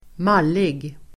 Ladda ner uttalet
mallig adjektiv, stuck-up , superior , snooty Uttal: [²m'al:ig] Böjningar: malligt, malliga Synonymer: arrogant, högdragen, högfärdig, kaxig, självbelåten, självgod, snorkig, stroppig Definition: högfärdig, överlägsen, snorkig